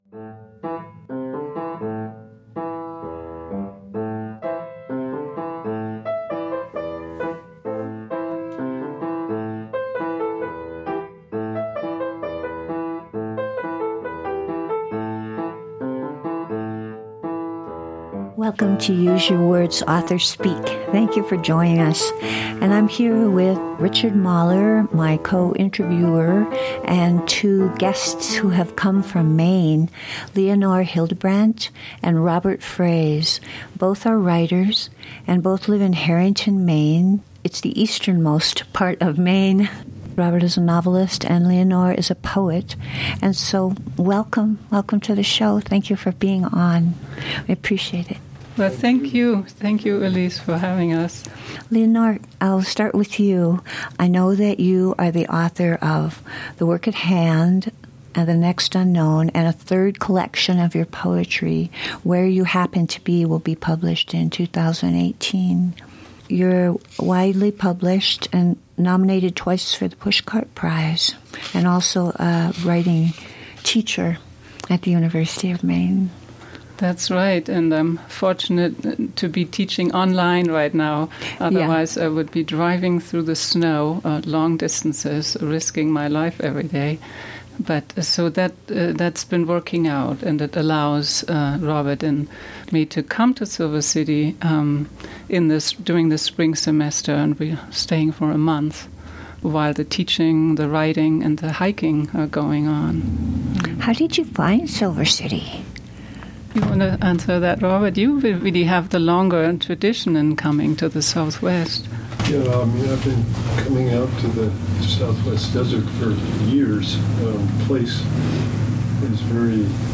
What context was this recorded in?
Use Your Words! is broadcast live on the 2nd & 4th Fridays of the month at 4:30pm and rebroadcast the following Fridays at the same time.